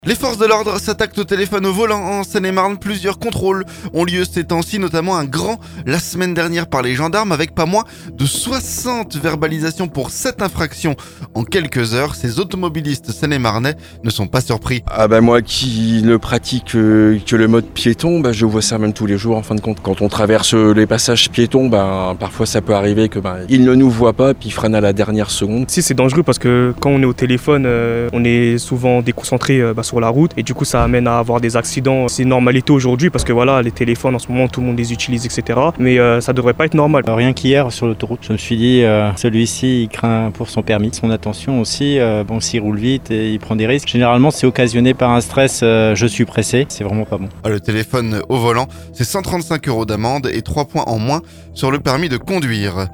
Notamment un grand la semaine dernière par les gendarmes, avec pas moins de 60 verbalisations en quelques heures. Ces automobilistes seine-et-marnais ne sont pas surpris.